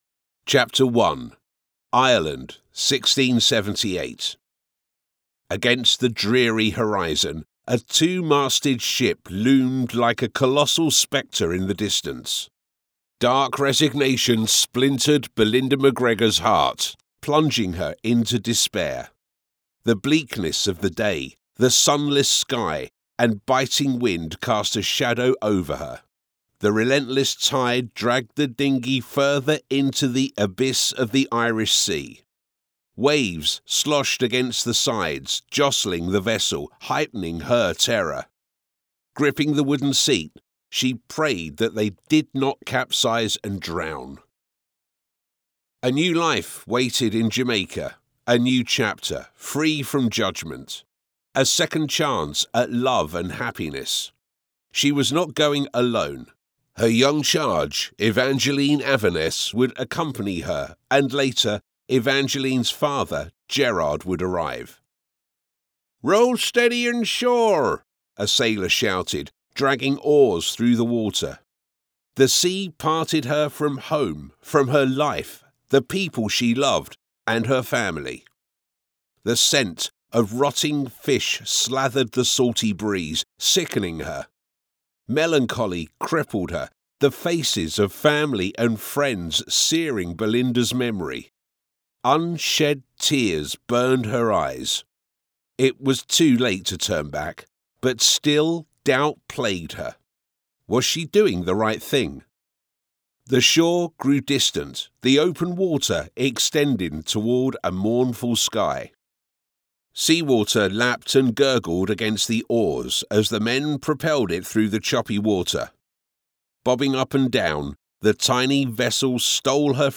Never any Artificial Voices used, unlike other sites.
Male
English (British)
Adult (30-50), Older Sound (50+)
From engaging e-learning modules to dynamic commercials and polished documentaries, I deliver high-quality recordings from my broadcast quality home studio.
Audiobooks
Gothic Romance